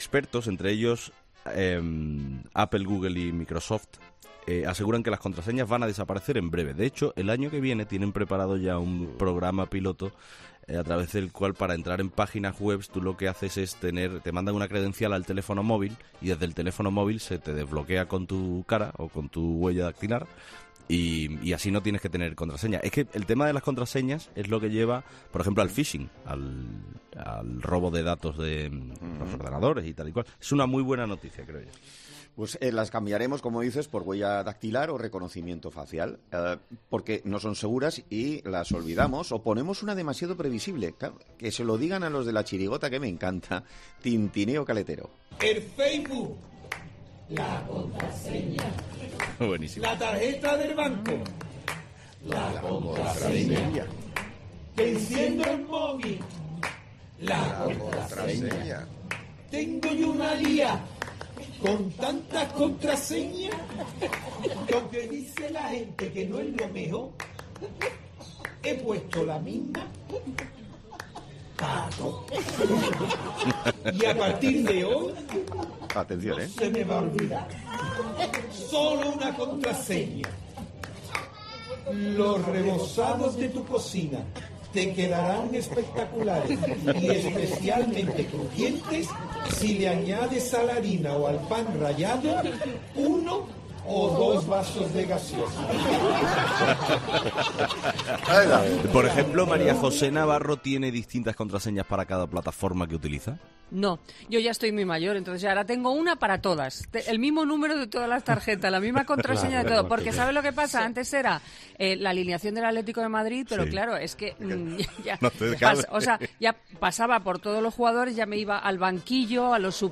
Tras conocer los detalles de la noticia, los colaboradores presentes en el programa han explicado cómo viven, cada uno en su caso, el tema de las contraseñas.